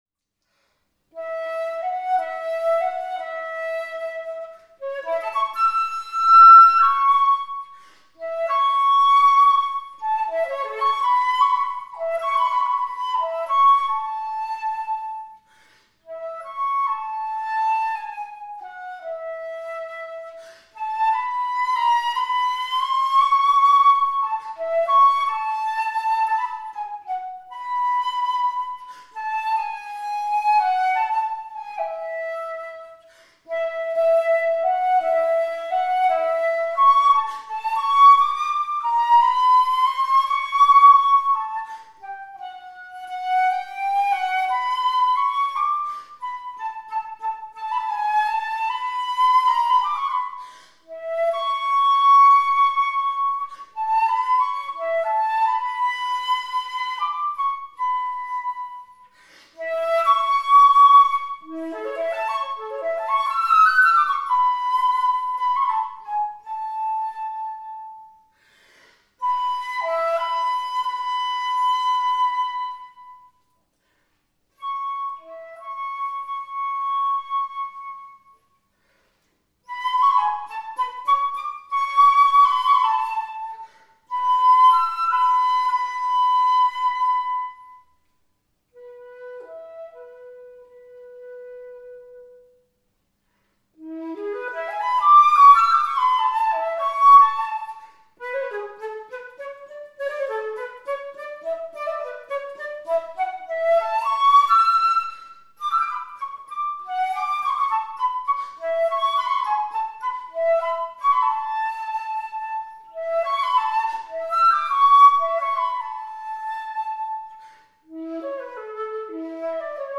Conical ring-key flute – Mahillon, C
Materials Cocuswood, nickel silver
This conical ring-key flute by Charles Boromée MAHILLON (1813-1887) was totally restored and now sounds fantastic.
The flute is made of finely selected cocuswood with nickel silver keywork, rings and crown tip.
This flute plays at a pitch of a=442 Hz with a wonderful luminous sound.